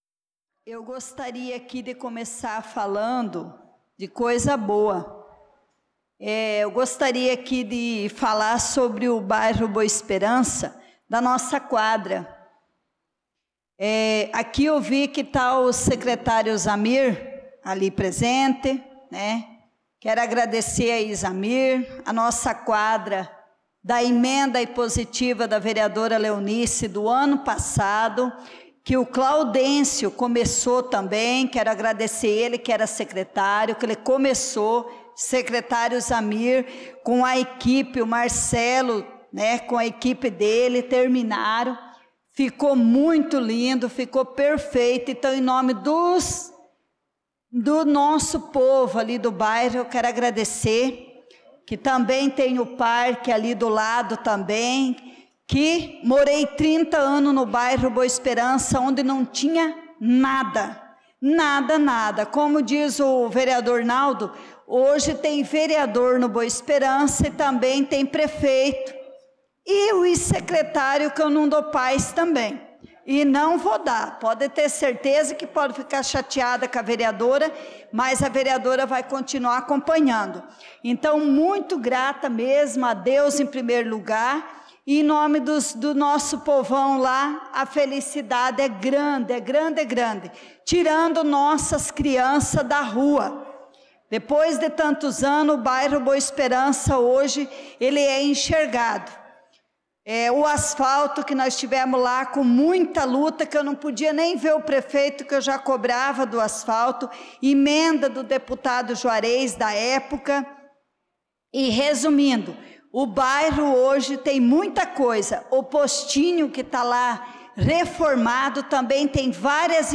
Pronunciamento da vereadora Leonice Klaus na Sessão Ordinária do dia 07/07/2025.